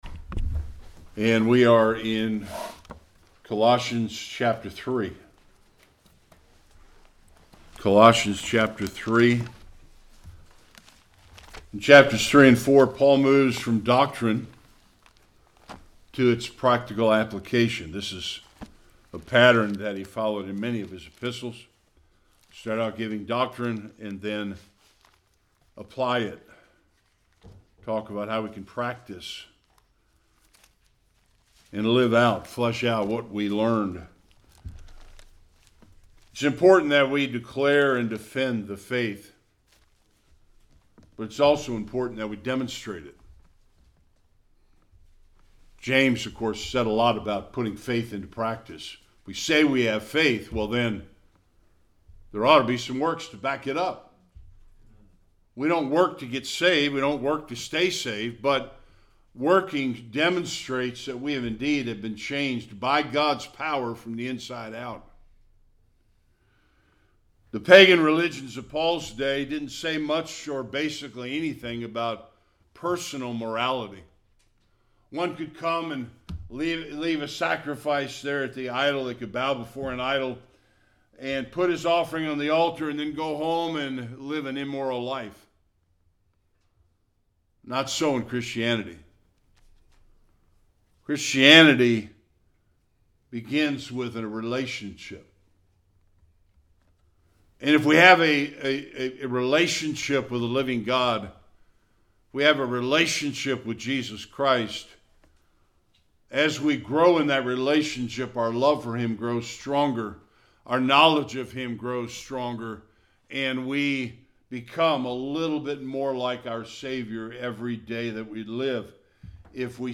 1-4 Service Type: Sunday Worship The Apostle Paul moves from doctrine to practical Christian living.